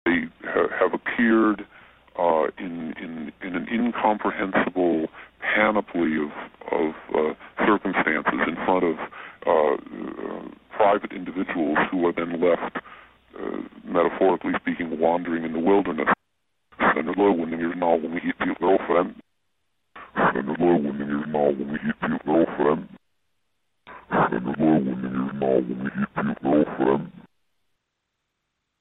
最大の難点は、 ヴォイスチェンジャーによって音声が聞きづらくなっていたことだった。
(mp3) というリバース・スピーチが現れていた。